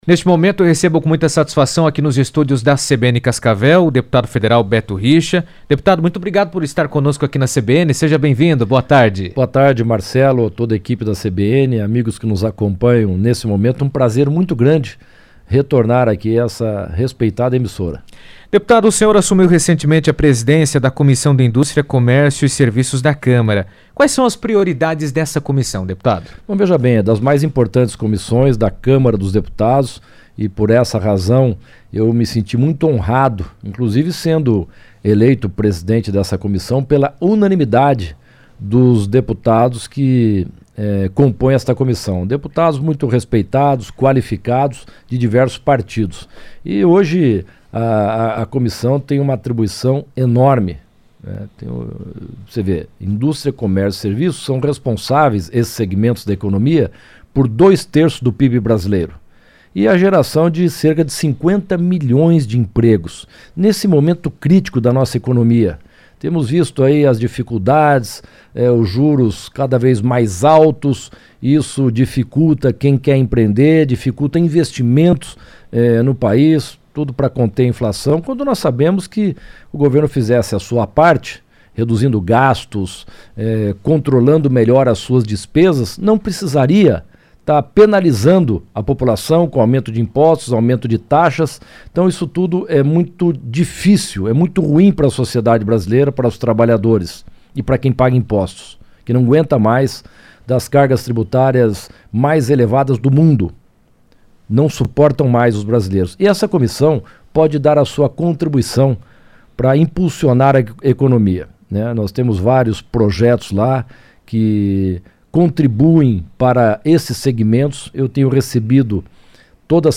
Em entrevista à CBN Cascavel, o deputado federal Beto Richa destacou a importância da Comissão de Indústria, Comércio e Serviços da Câmara dos Deputados, da qual faz parte, ressaltando seu papel estratégico na formulação de políticas públicas voltadas ao fortalecimento da economia nacional.